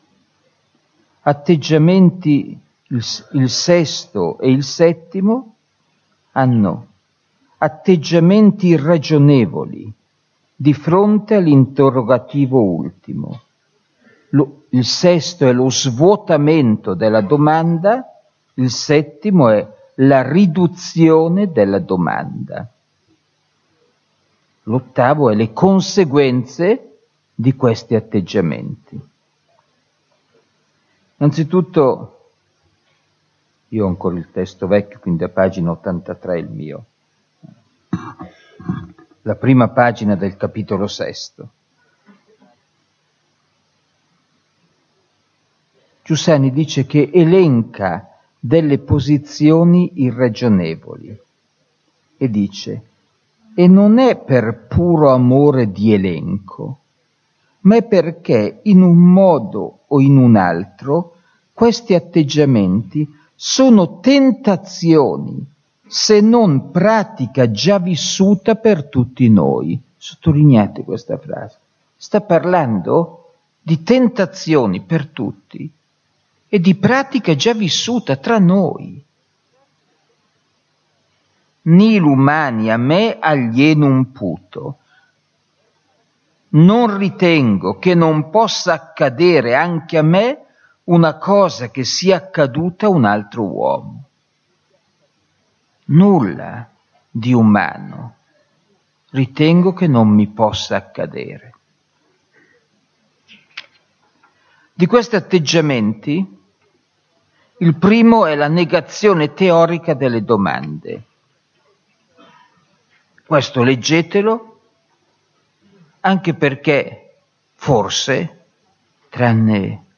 (tratto da una catechesi)